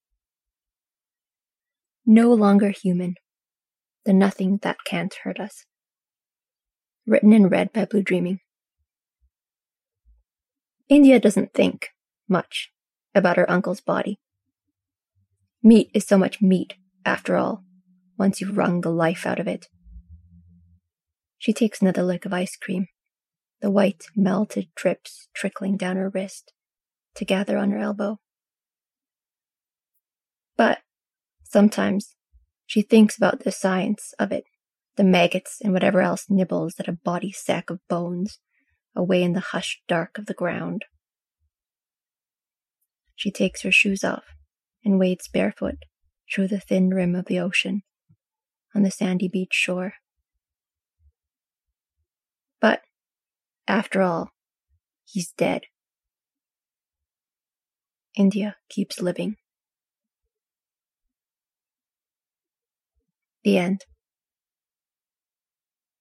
Podficcer's notes: A no-music version, and two versions with music recorded on different mics.
no music:
01+no+longer+human+(no+music).mp3